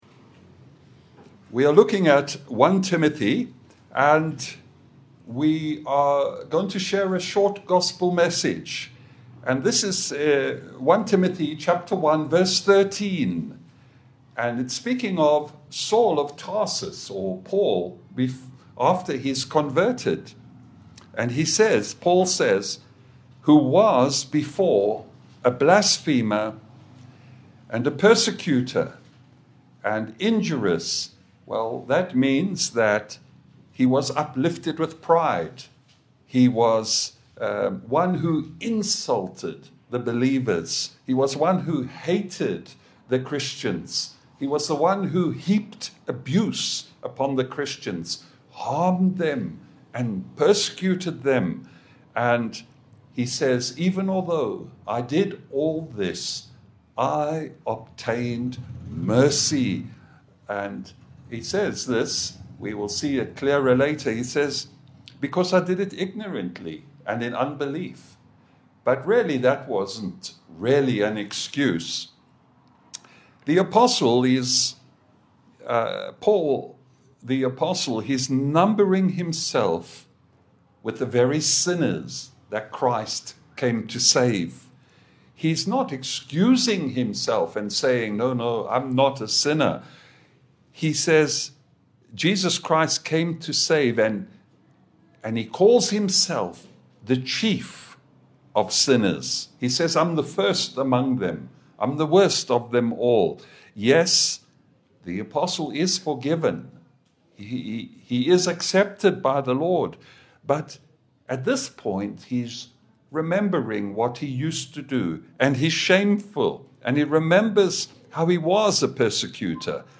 1Tim 1:15 Service Type: Gospel Service « Of Good Works The First Duty of the Christian.